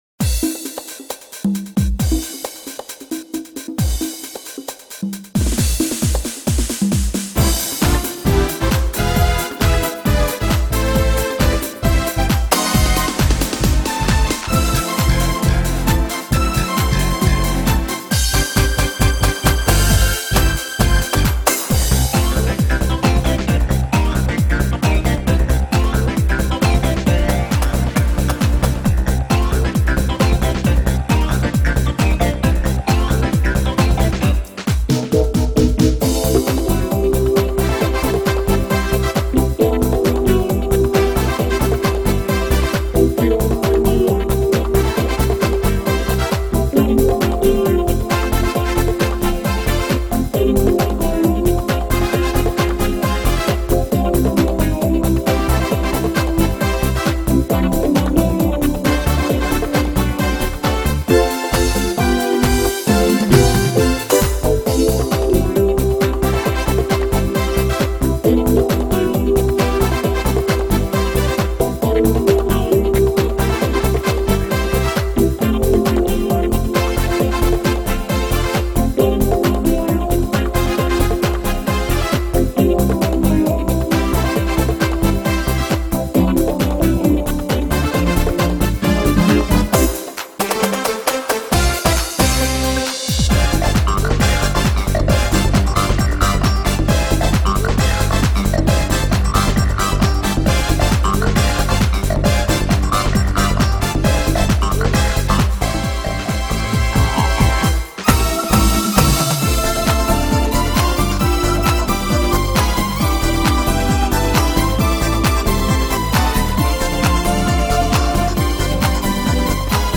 Electone Player